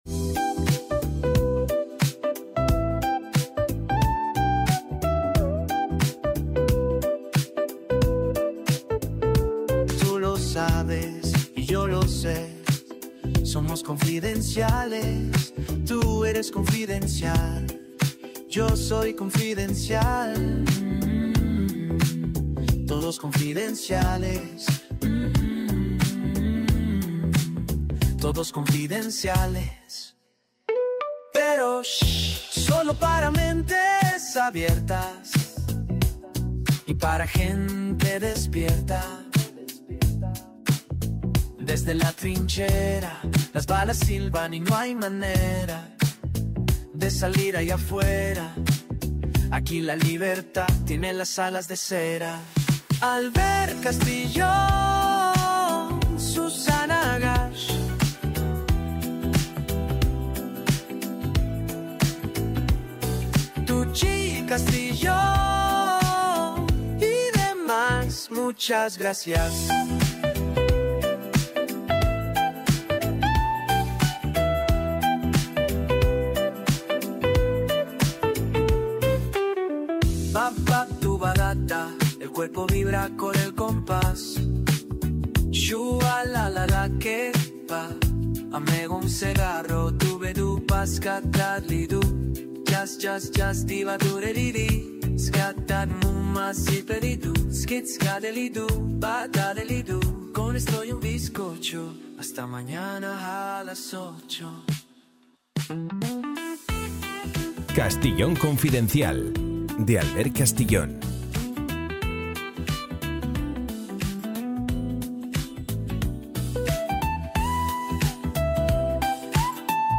Canción creada con inteligencia artificial